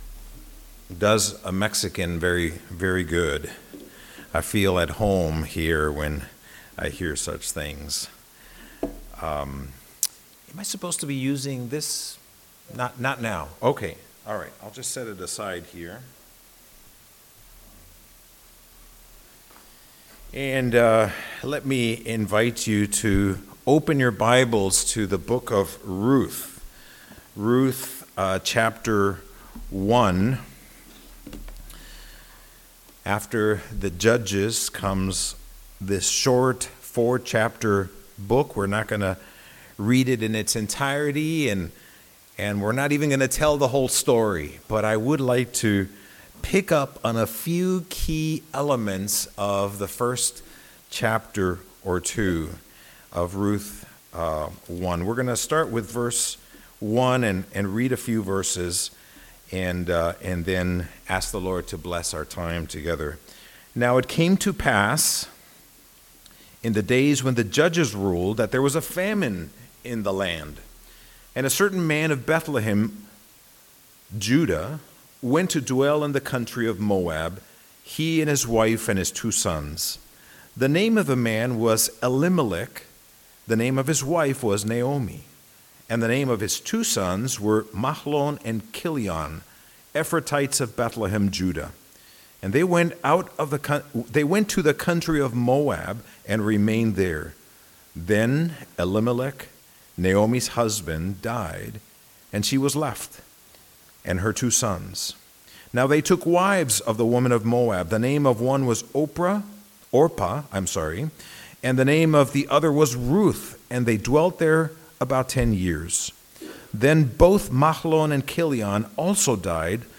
Passage: Ruth 1 Service Type: Sunday « OPAL